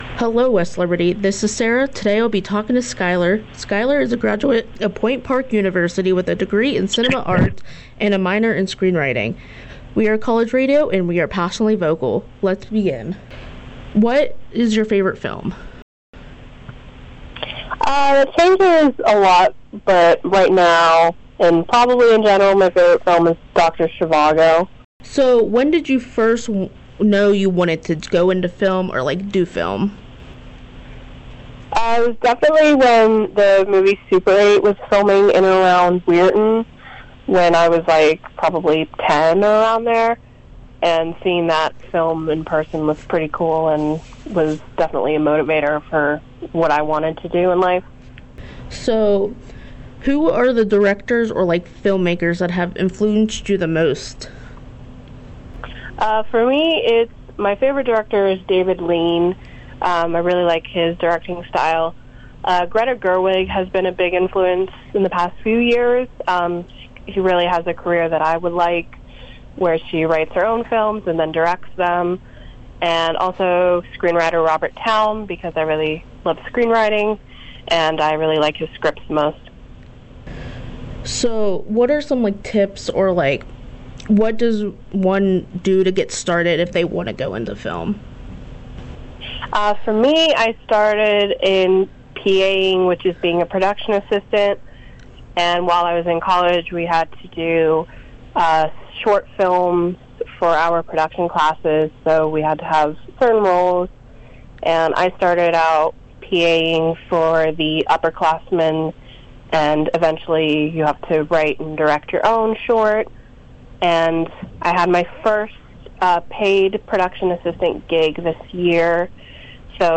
WGLZ Radio 91.5WGLZ Radio 91.5